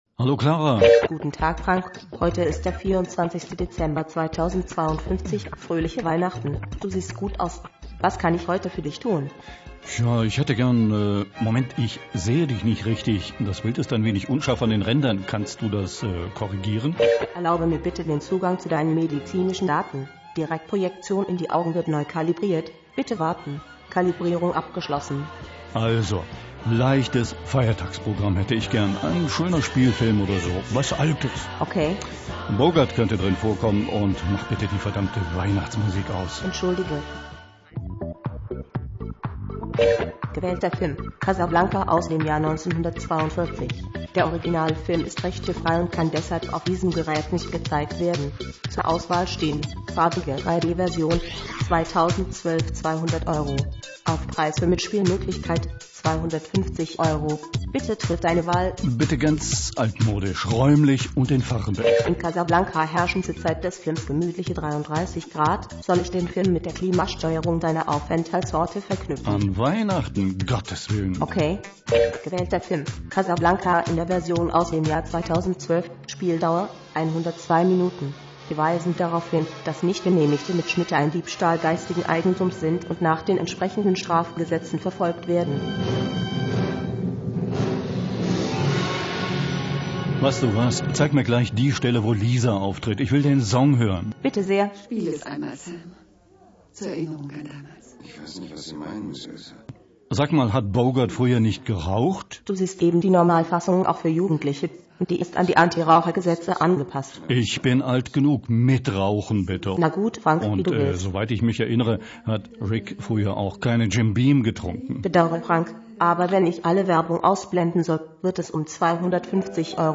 Beim Aufräumen entdeckt: Eine Zukunftsvision, die ich vor knapp einem Vierteljahrhundert fürs Radio gebastelt habe – unter der Überschrift: „Fernsehen in 50 Jahren“: Ein älterer Mann will zu Weihnachten einen Film sehen und muss dafür mit seinem KI-Sprachassistenten ein paar kleinere und größere Kämpfe ausfechten.